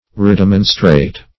Search Result for " redemonstrate" : The Collaborative International Dictionary of English v.0.48: Redemonstrate \Re*dem"on*strate\ (r?*d?m"?n*str?t or r?`d?*m?n"-str?t), v. t. To demonstrate again, or anew.
redemonstrate.mp3